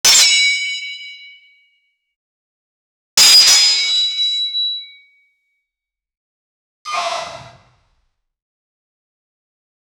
army swords and war cry sfx
army-swords-and-war-cry-c3xwyib6.wav